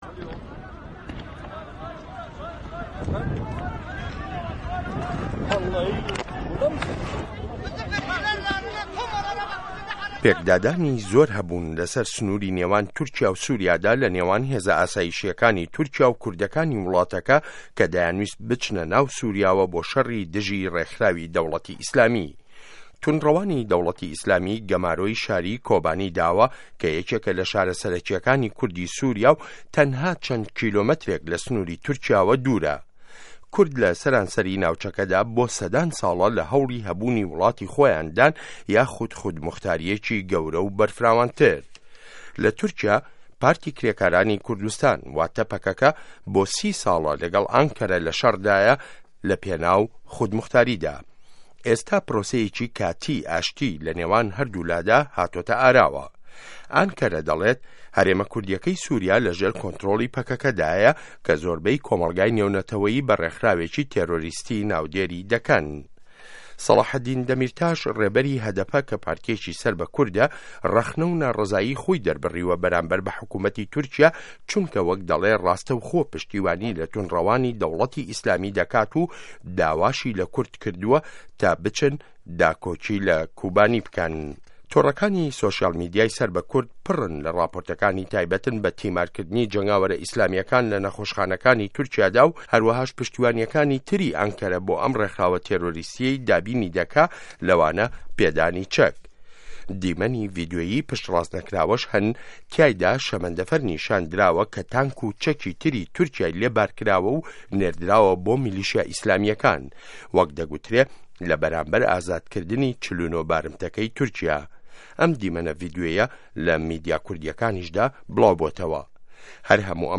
ڕاپۆرتی تورکیا سوریا کورد